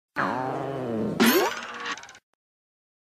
Anime Hungry Tummy Sound Effect - Botão de Efeito Sonoro